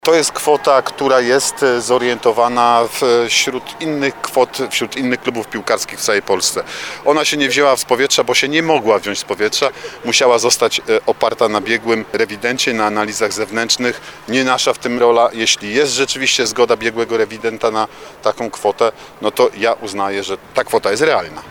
Na jakiej podstawie te usługi wyceniono na ponad 3 miliony złotych? Pytamy zastępcę prezydenta Nowego Sącza Artura Bochenka.